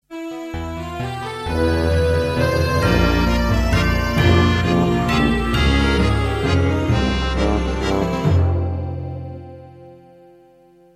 Studio midi* numérique automatisé
Piano électronique 88 touches Yamaha PF80